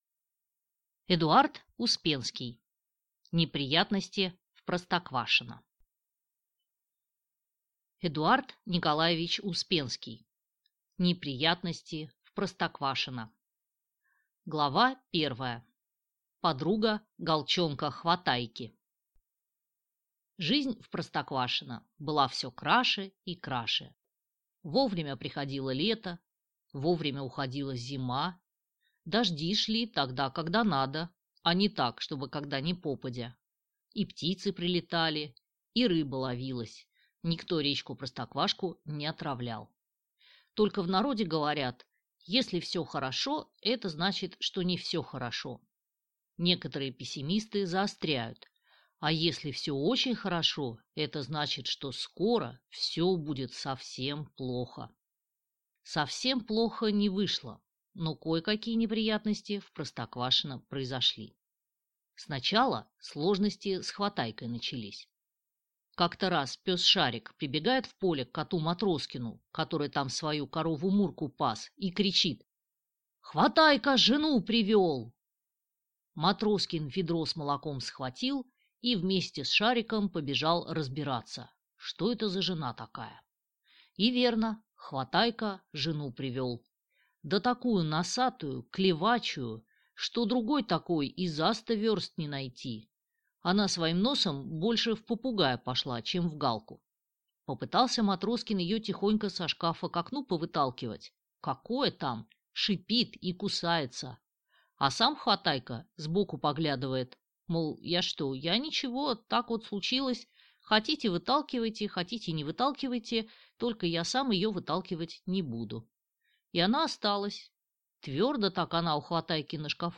Аудиокнига Неприятности в Простоквашино | Библиотека аудиокниг